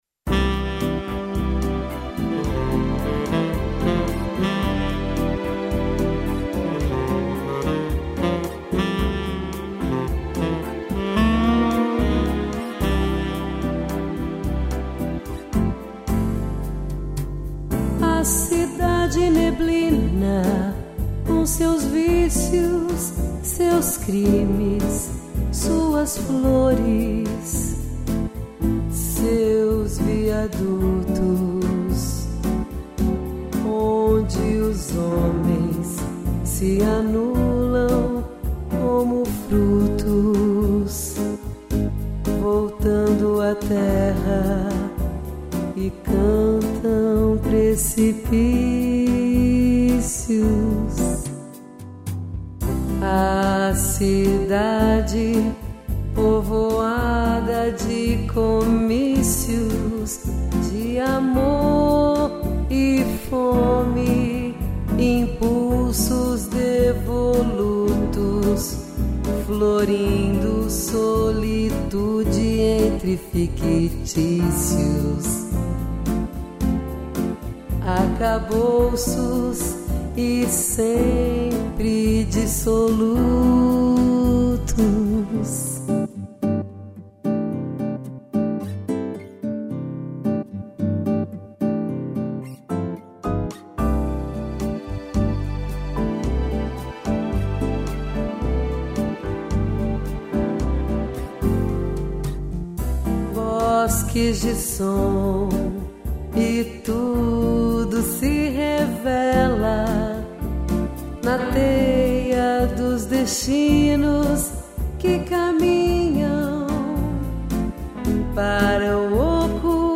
bossa